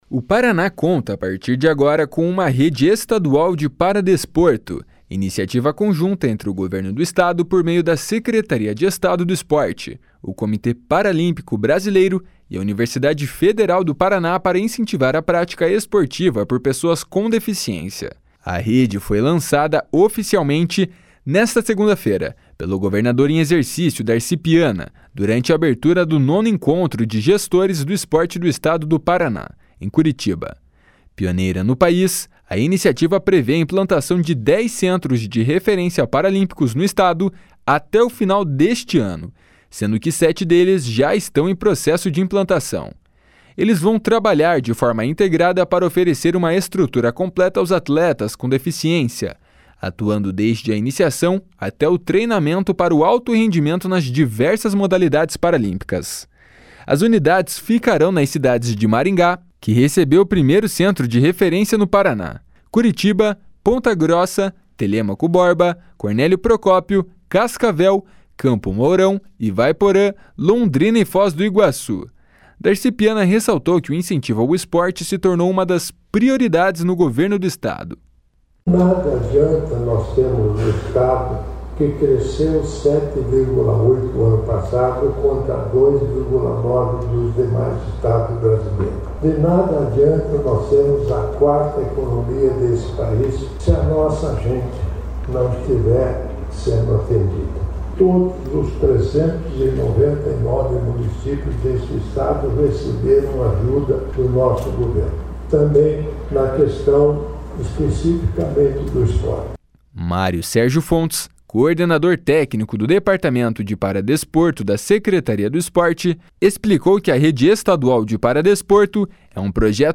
A rede foi lançada oficialmente nesta segunda-feira pelo governador em exercício Darci Piana, durante a abertura do 9º Encontro de Gestores do Esporte do Estado do Paraná, em Curitiba. Pioneira no País, a iniciativa prevê a implantação de 10 Centros de Referência Paralímpicos no Estado até o final deste ano, sendo que sete deles já estão em processo de implantação.
// SONORA DARCI PIANA //